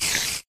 spider2.ogg